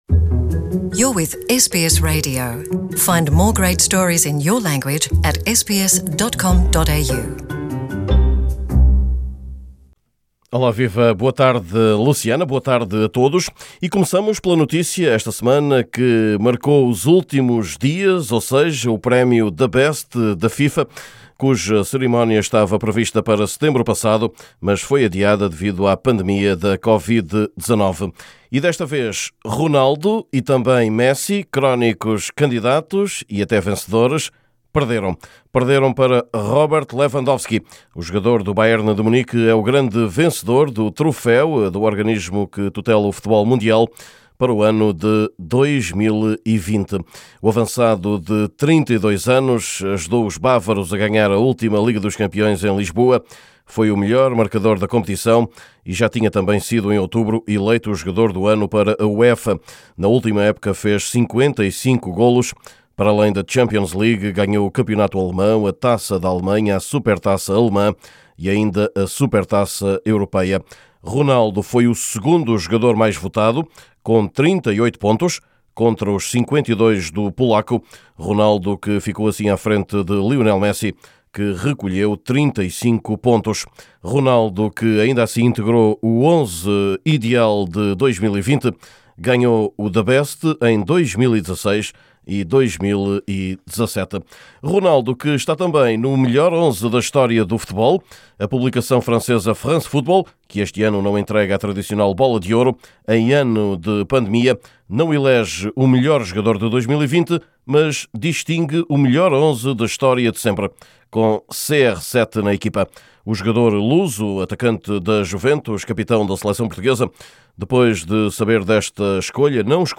Boletim esportivo de hoje traz ainda as últimas do futebol nacional português, com uma lesão importante no FC Porto: Pepe.